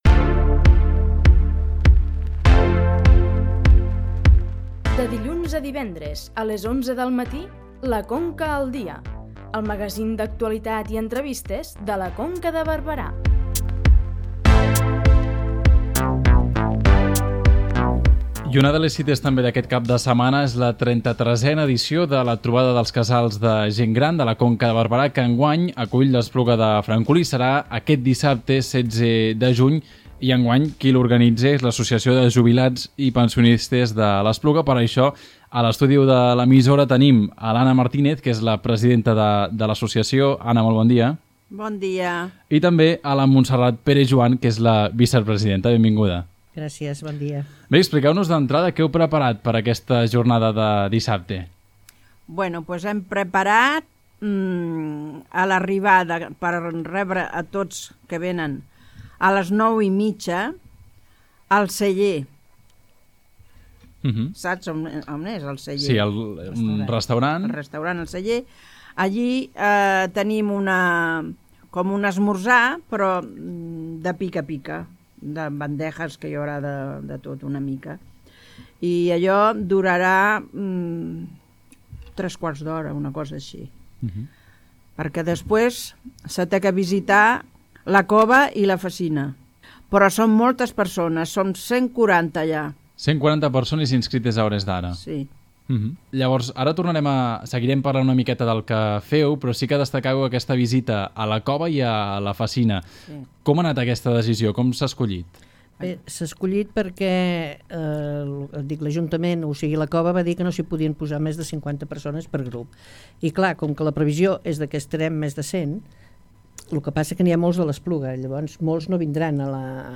Entrevista-WEB-Trobada-Gent-Gran-Conca.mp3